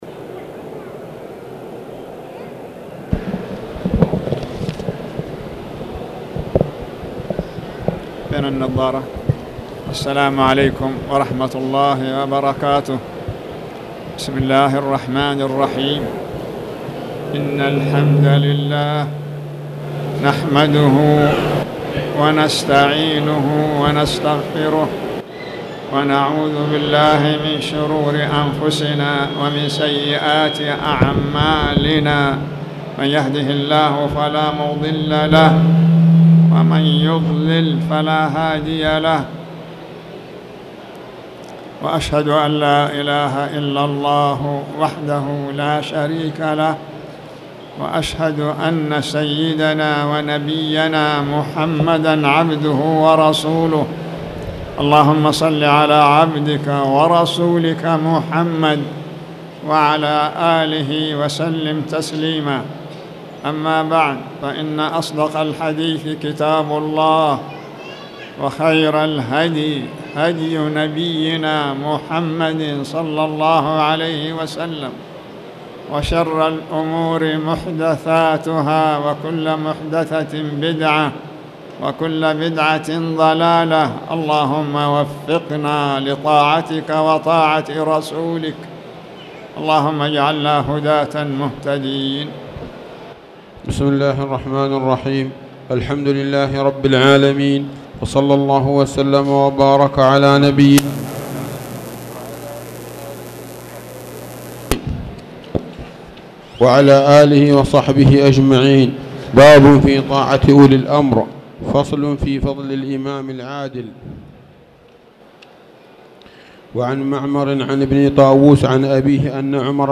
تاريخ النشر ٦ ذو القعدة ١٤٣٧ هـ المكان: المسجد الحرام الشيخ